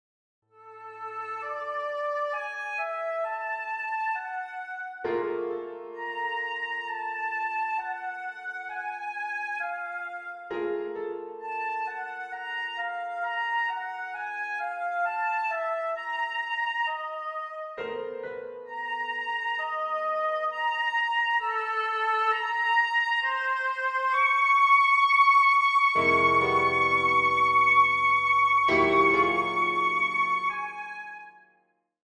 Excerpt from the First Movement